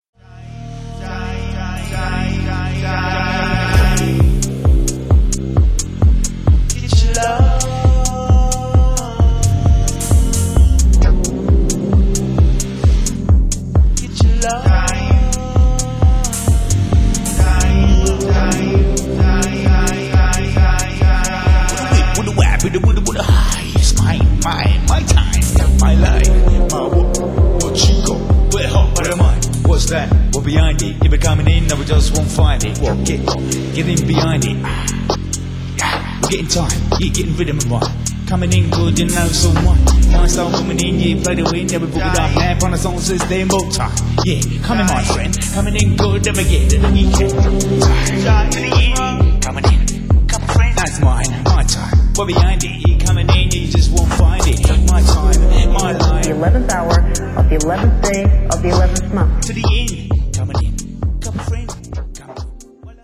Ambient - House